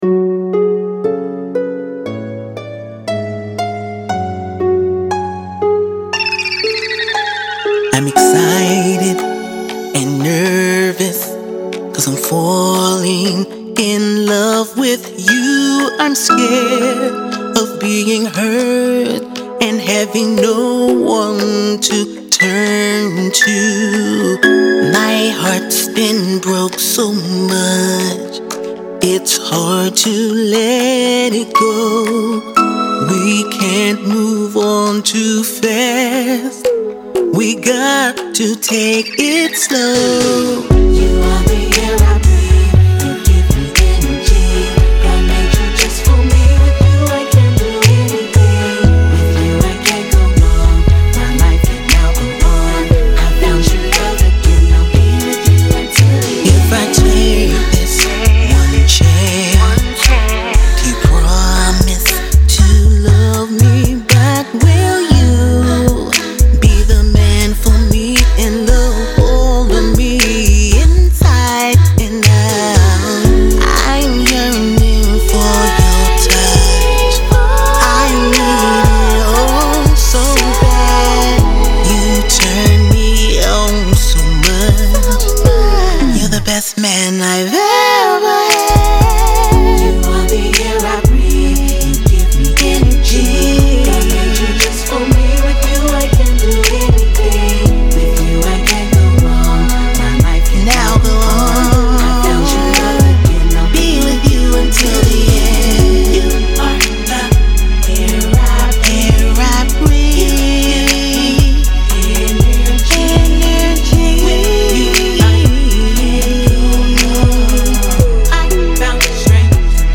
RnB
Description : This song is sexy, soulful and edgy!